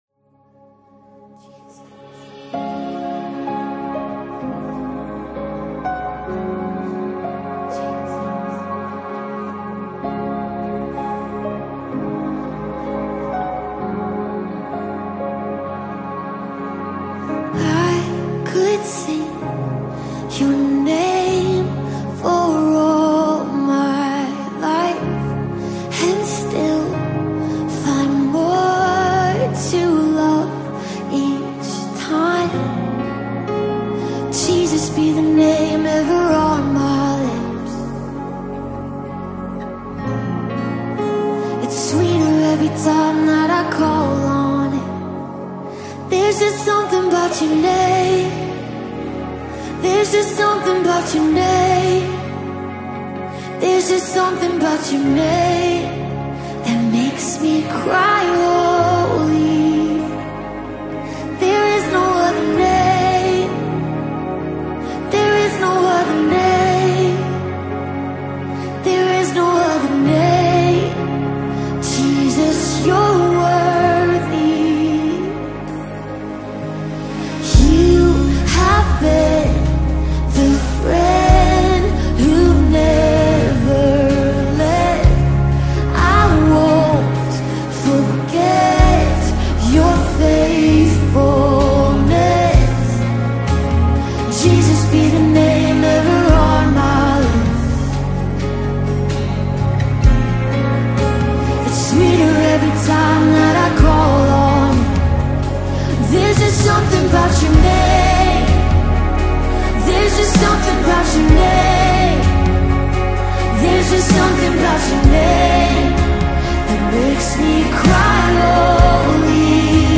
Gospel Songs